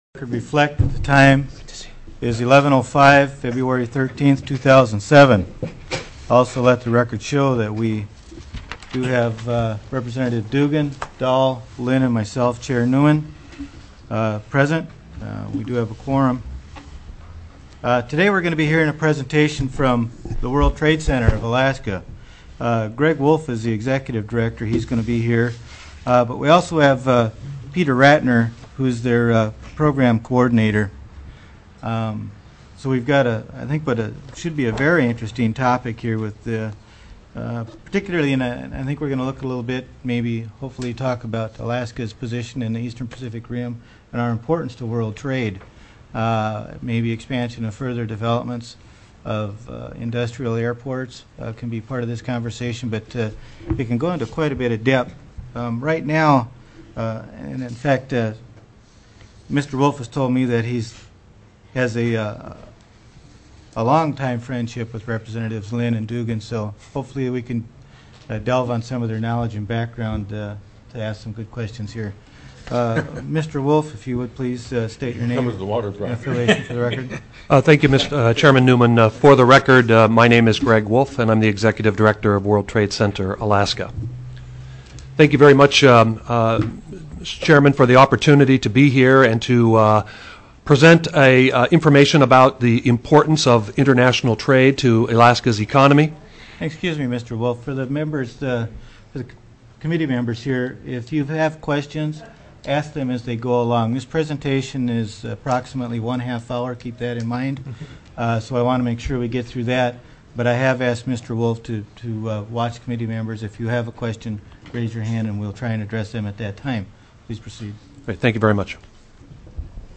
02/13/2007 11:00 AM House ECONOMIC DEV., TRADE, AND TOURISM
CHAIR MARK NEUMAN called the House Special Committee on Economic Development, International Trade and Tourism meeting to order at 11:05:28 AM .